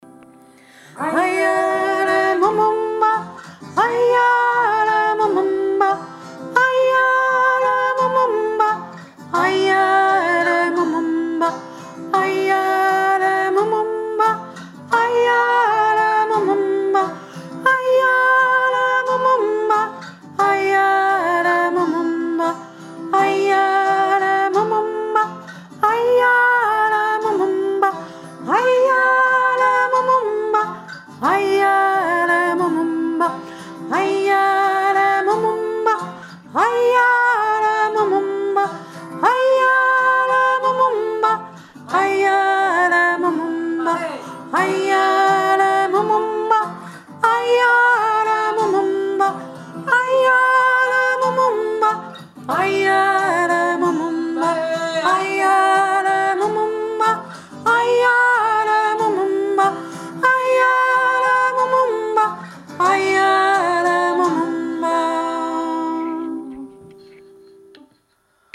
AYELE MUMUMBA 3. Stimme - hoch
ayele-hohe-stimme.mp3